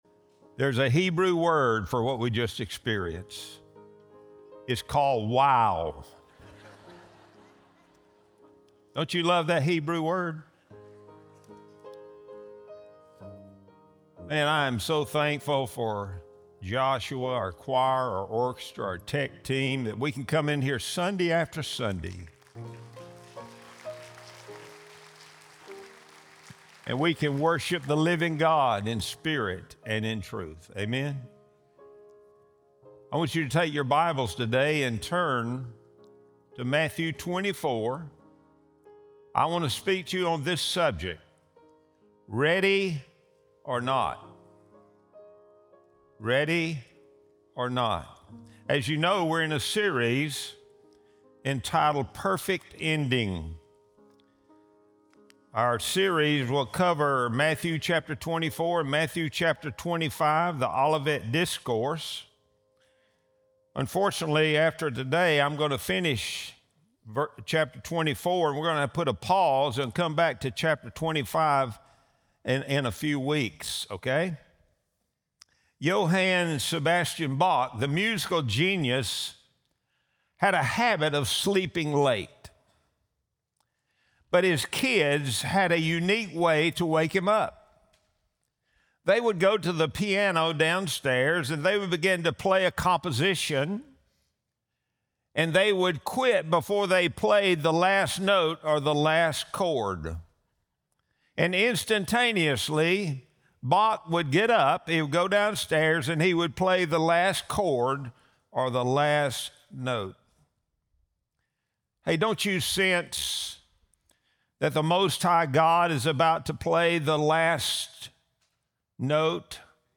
Sunday Sermon | November 2, 2025